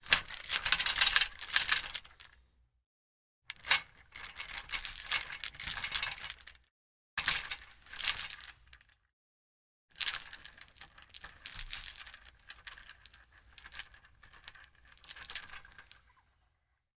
随机的 "链状粗的拨浪鼓钩在金属上1
描述：链厚的拨浪鼓挂在metal1.wav上
标签： 钩状 金属 拨浪鼓
声道立体声